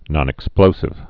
(nŏnĭk-splōsĭv)